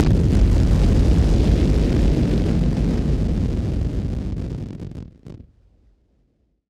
BF_SynthBomb_B-04.wav